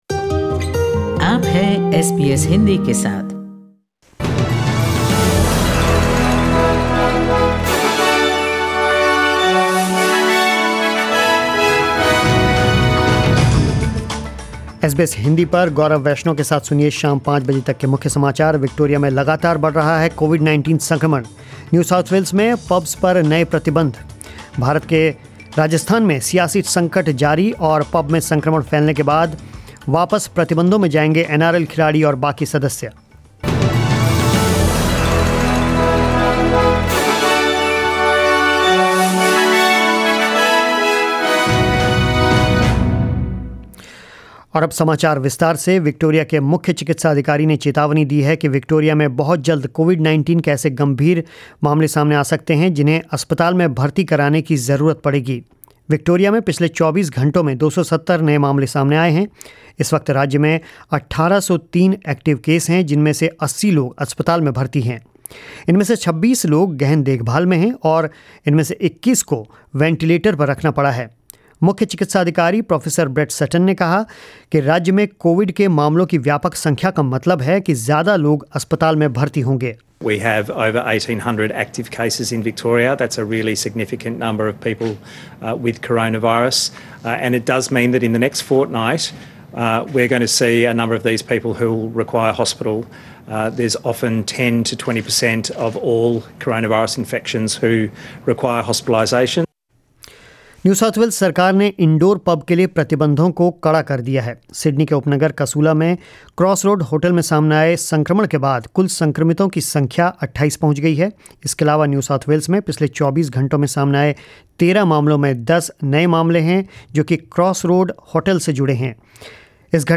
News in Hindi 14 July 2020